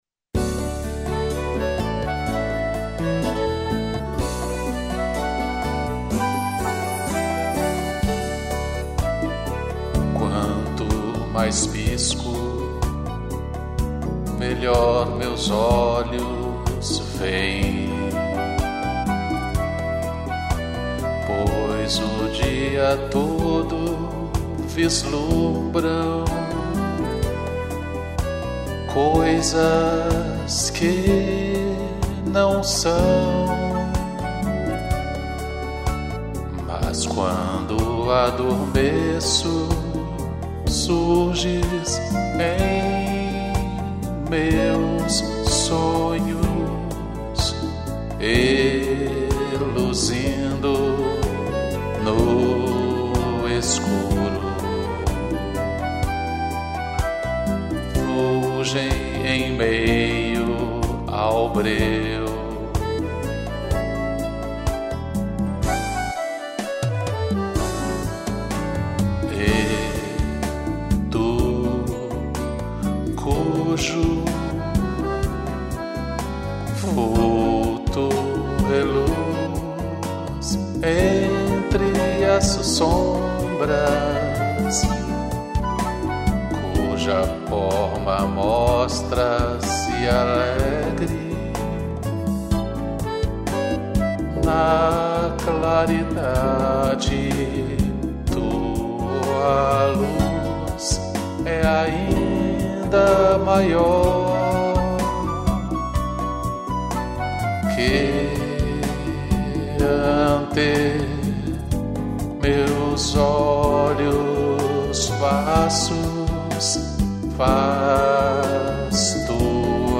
piano, acordeão e fláuta